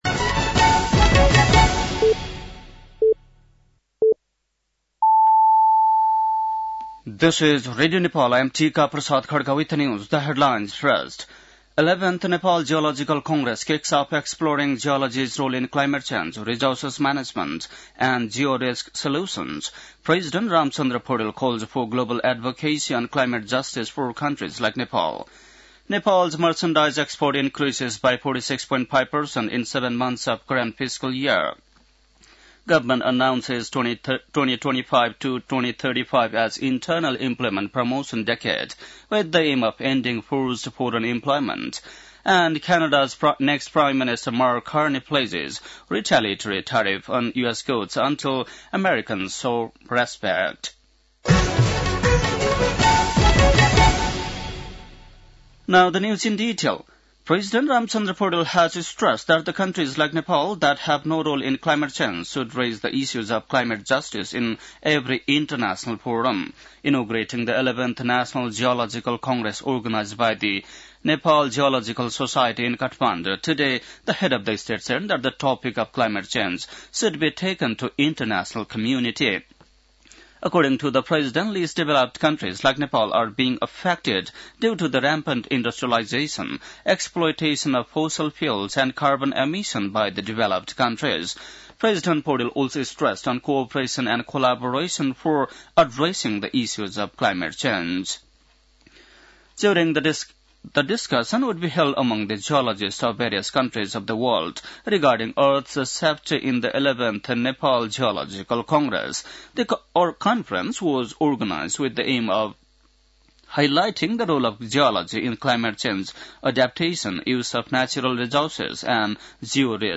An online outlet of Nepal's national radio broadcaster
बेलुकी ८ बजेको अङ्ग्रेजी समाचार : २७ फागुन , २०८१
8-pm-english-news-11-26.mp3